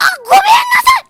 Worms speechbanks
ouch.wav